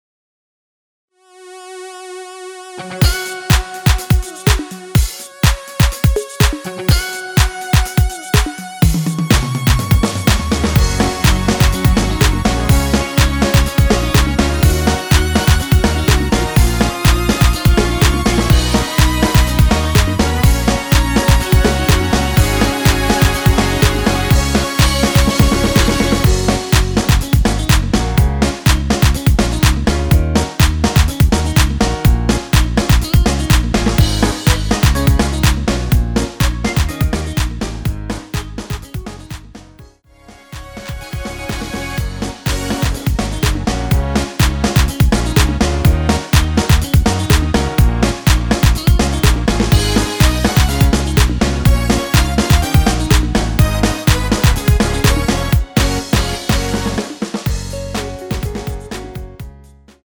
원키에서(-1)내린 MR입니다.
Gb
앞부분30초, 뒷부분30초씩 편집해서 올려 드리고 있습니다.